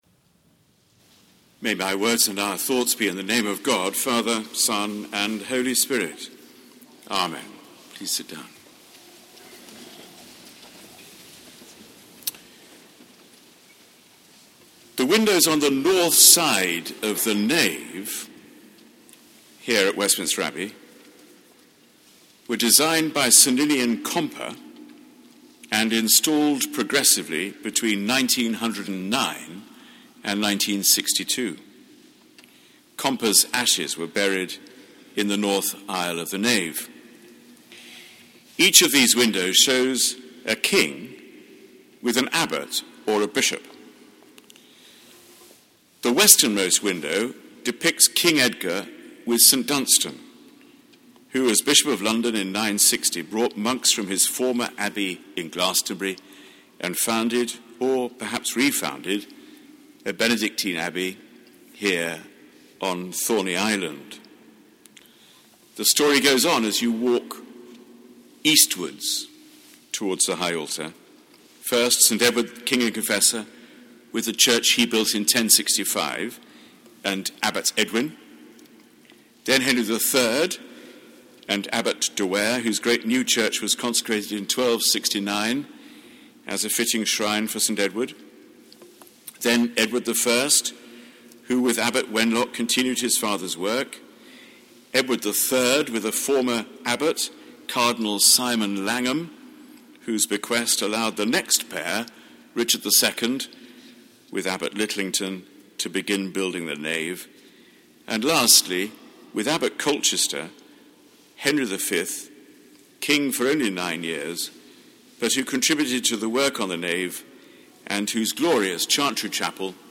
Sermon given at Sung Eucharist on All Saints' Day: Monday 1 November 2010